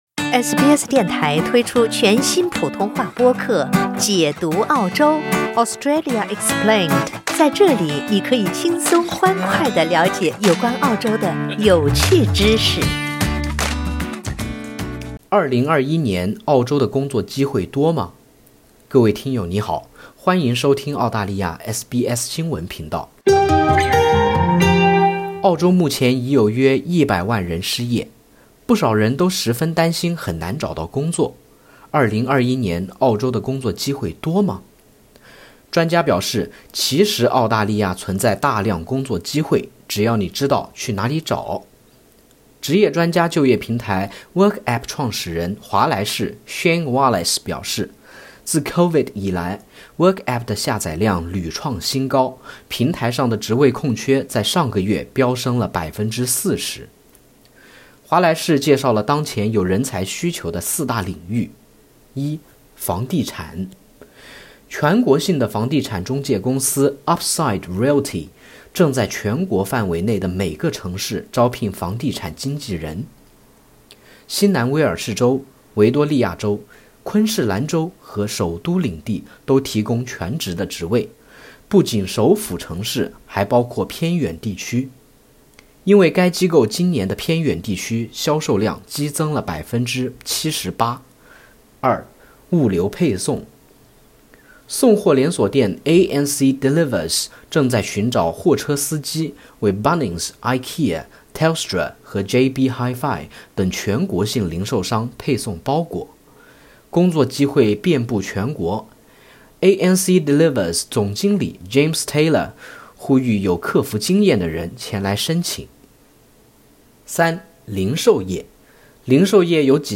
专家表示，其实澳大利亚存在大量工作机会，只要你知道去哪里找。点击上图收听报道。